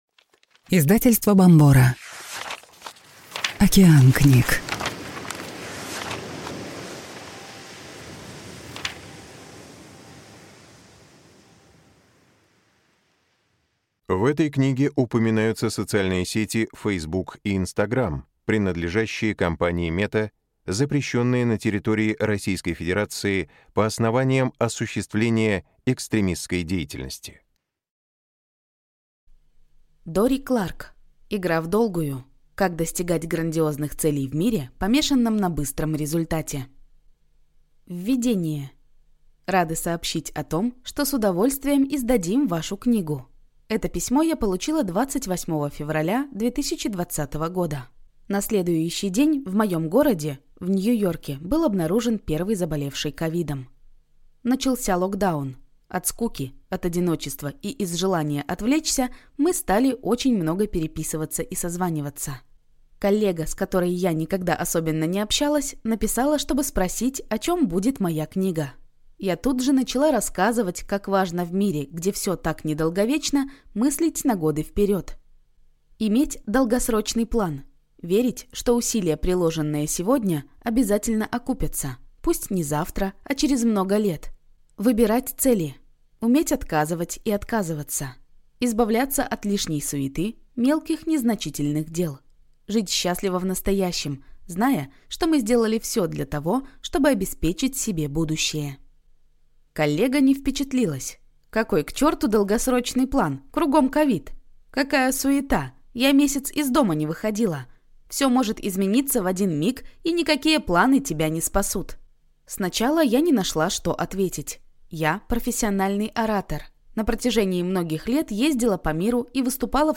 Аудиокнига Игра вдолгую. Как достигать грандиозных целей в мире, помешанном на быстром результате | Библиотека аудиокниг